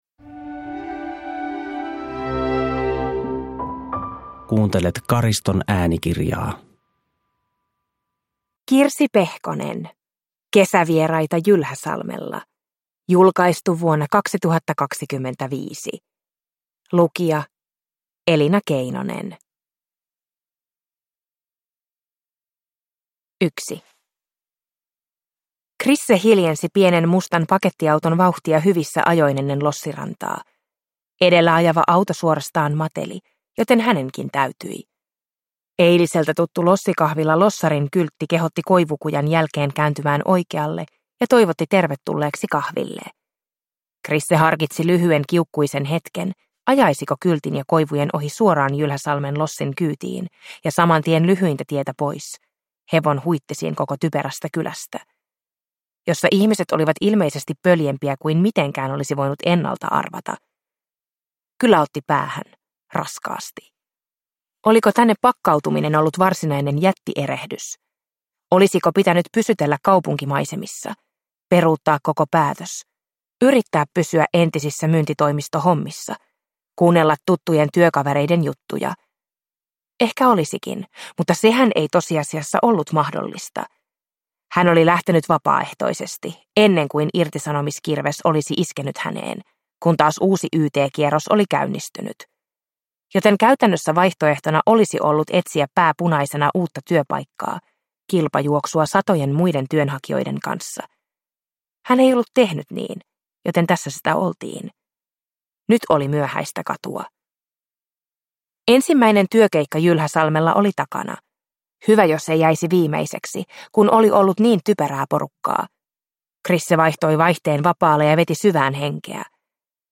Kesävieraita Jylhäsalmella (ljudbok) av Kirsi Pehkonen